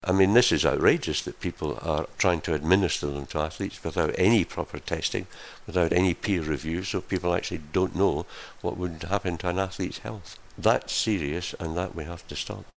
A questionfor Sir Craig Reedie head of WADA, the World Anti Doping Agency